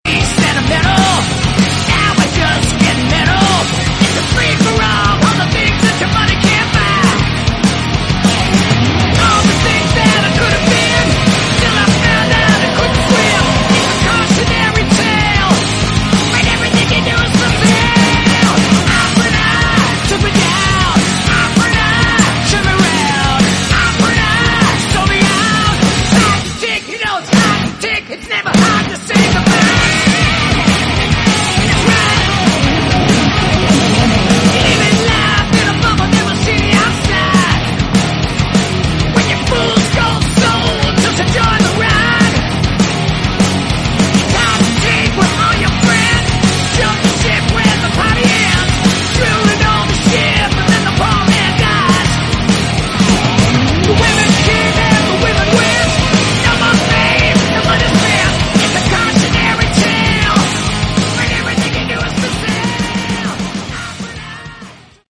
Category: Rock
vocals, guitar, bass
bass guitar